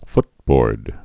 (ftbôrd)